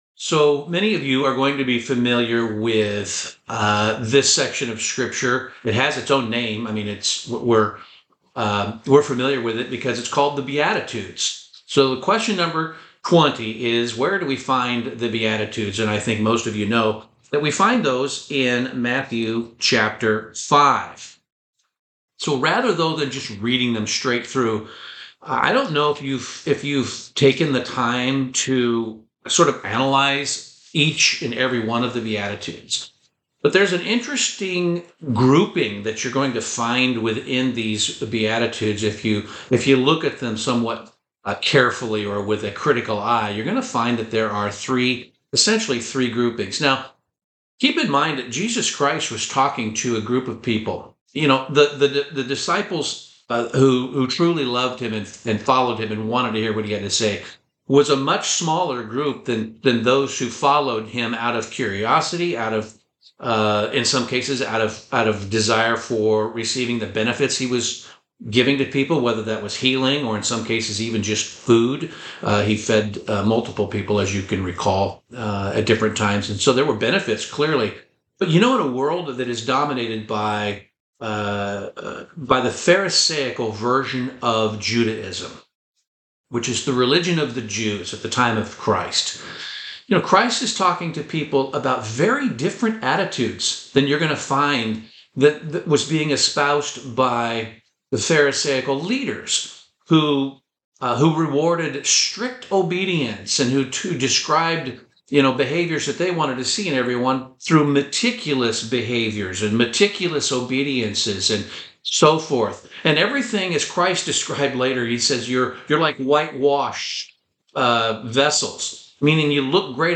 Bible Study - The Beatitudes